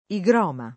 igroma [ i g r 0 ma ]